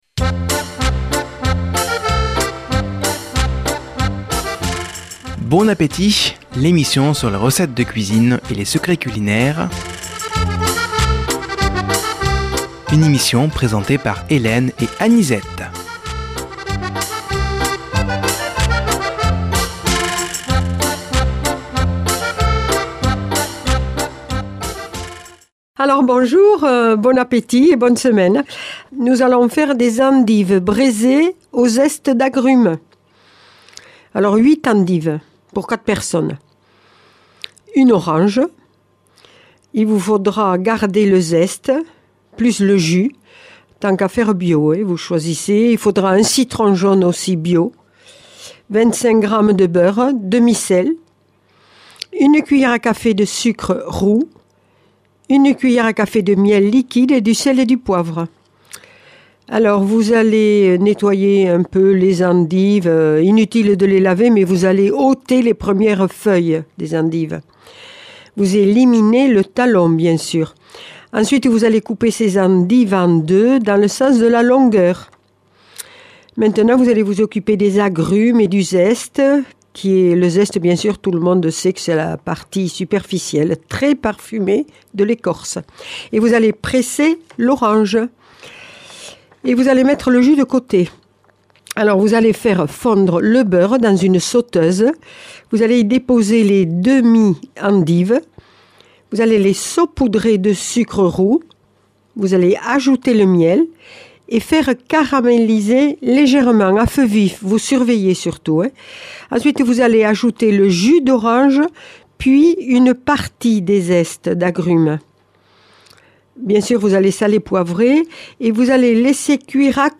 Présentatrices